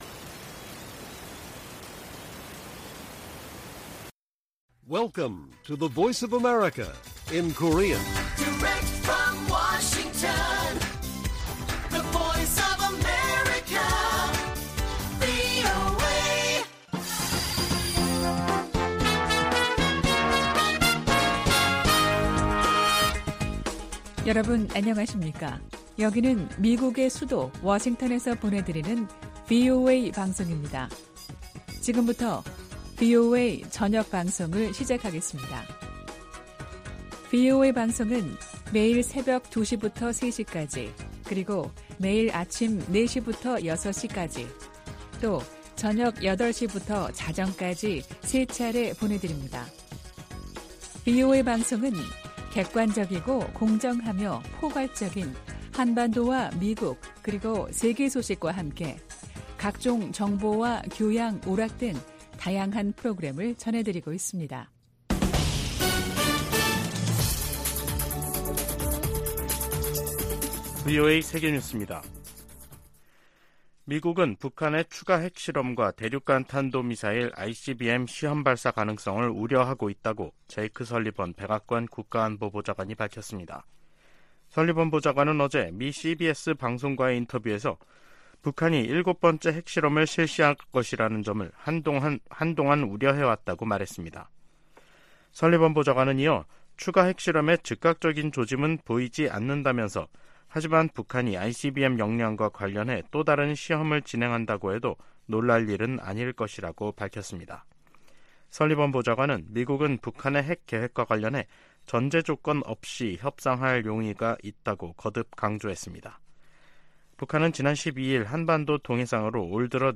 VOA 한국어 간판 뉴스 프로그램 '뉴스 투데이', 2023년 7월 17일 1부 방송입니다. 김여정 북한 노동당 부부장이 담화를 내고 대륙간탄도미사일 '화성-18형' 발사의 정당성을 주장하면서 미국을 위협했습니다. 인도네시아 자카르타에서 열린 제30차 아세안지역안보포럼(ARF) 외교장관회의에서 미한일 등 여러 나라가 북한의 탄도미사일 발사를 규탄했습니다. 미 상원에서 한국 등 동맹국의 방위비 분담 내역 의회 보고 의무화 방안이 추진되고 있습니다.